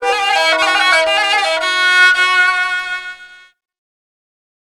Index of /90_sSampleCDs/Sonic Foundry (Sony Creative Software) - World Pop/Stringed Instruments/Chinese